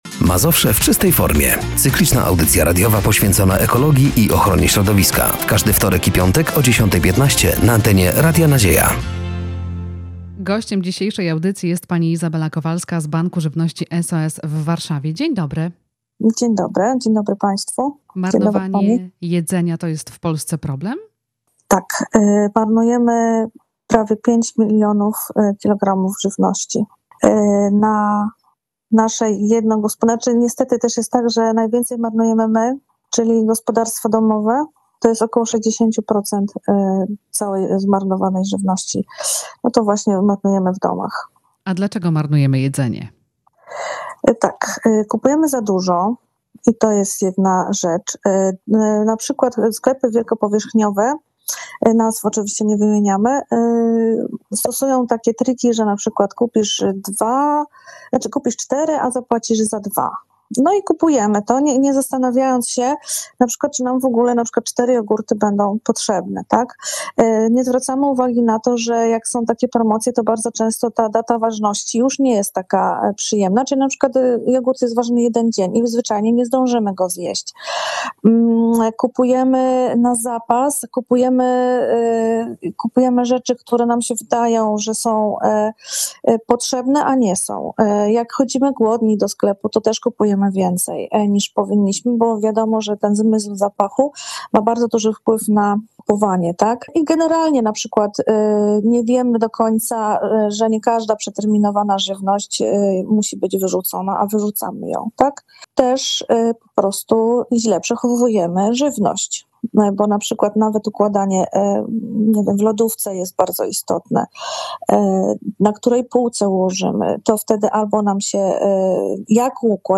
Zapraszamy do wysłuchania rozmowy. https